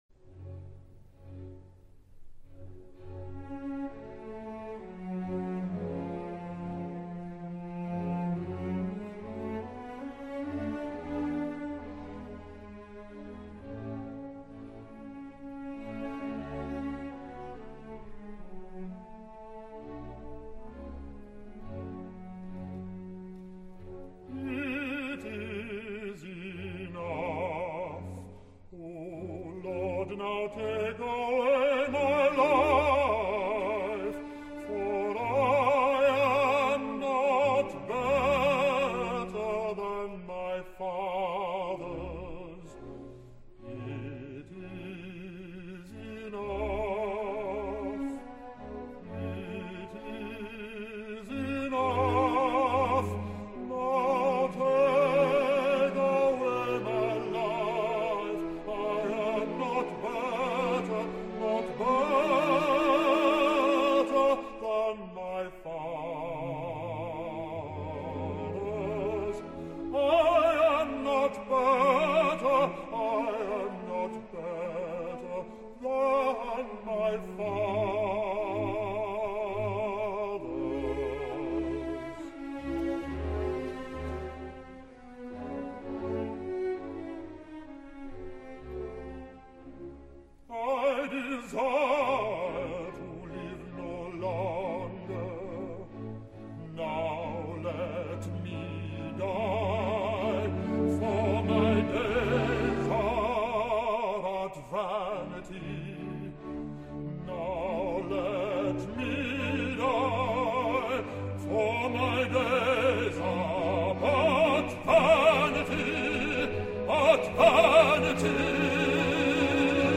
OROTORIO AND CONCERT REPERTOIRE: